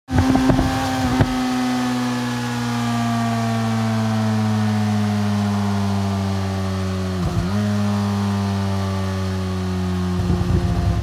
دانلود آهنگ موتور 10 از افکت صوتی حمل و نقل
جلوه های صوتی
دانلود صدای موتور 10 از ساعد نیوز با لینک مستقیم و کیفیت بالا
برچسب: دانلود آهنگ های افکت صوتی حمل و نقل دانلود آلبوم صدای موتورسیکلت از افکت صوتی حمل و نقل